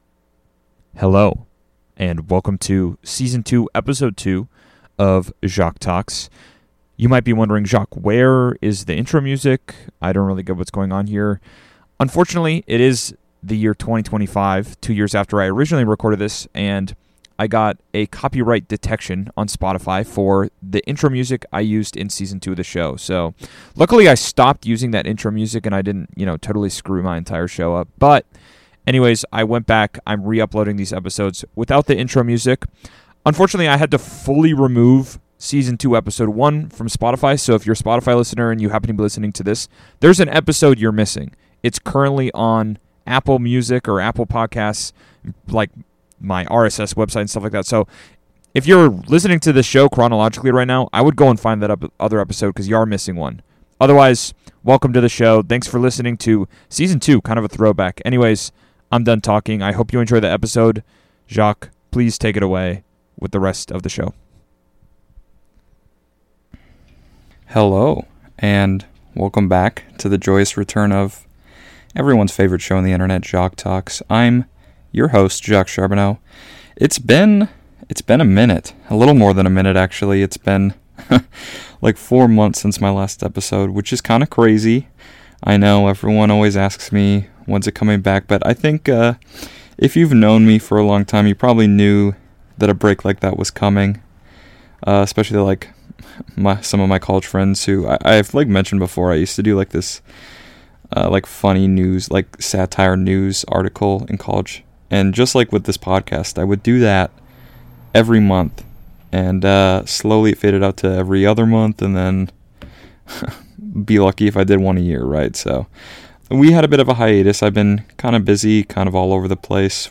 The intro/outro music was flagged for copyright infringement in 04/25, so I decided to just remove it instead of fighting.